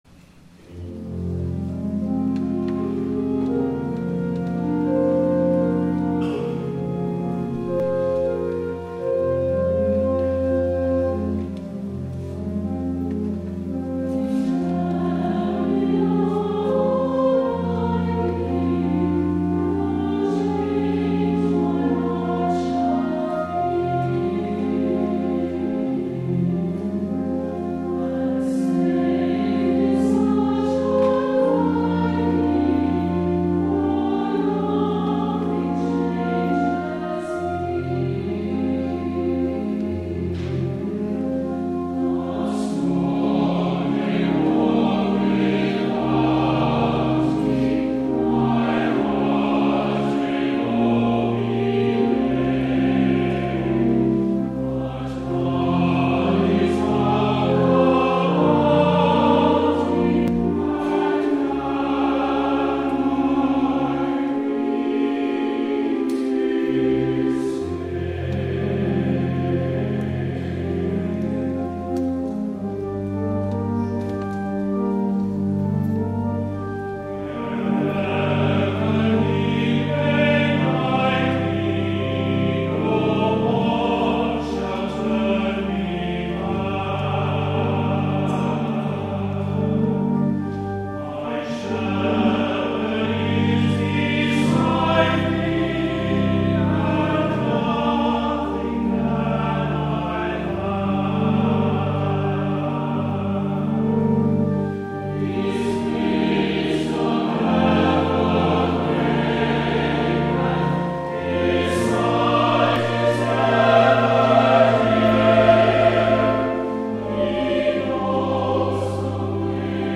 Morningside Presbyterian Church, Atlanta
THE ANTHEM